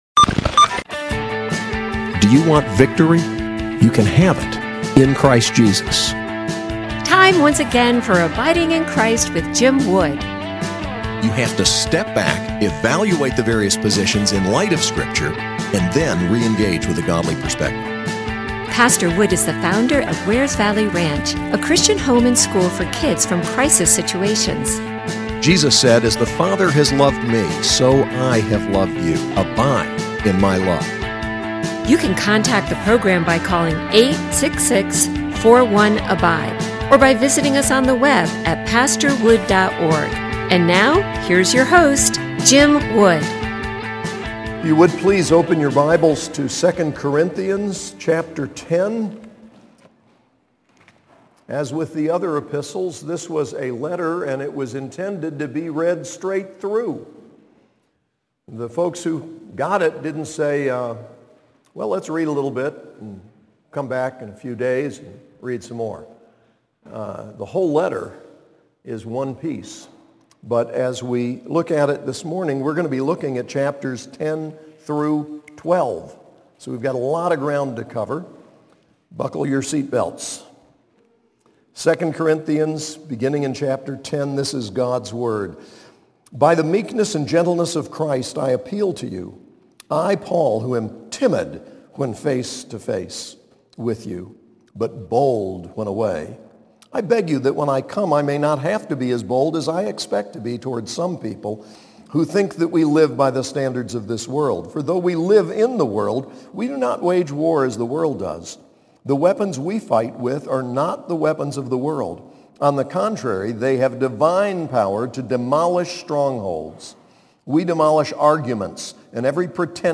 SAS Chapel: 2 Corinthians 10-12